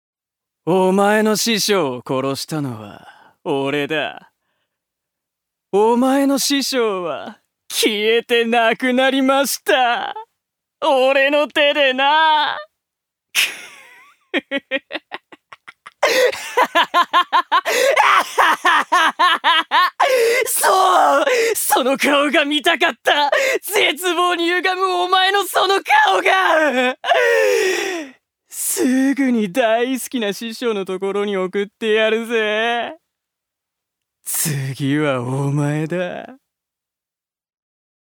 所属：男性タレント
セリフ６